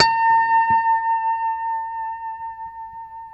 B5 PICKHRM1B.wav